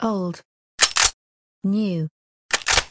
p90_no_longer_reuses_the_aug_boltpu.mp3